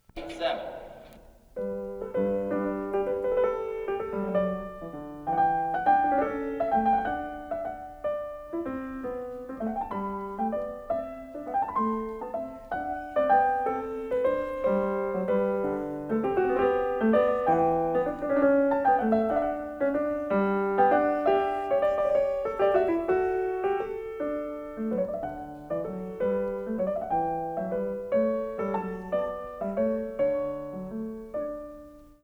The approved take is much faster than take 4.